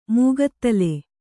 ♪ mūgattale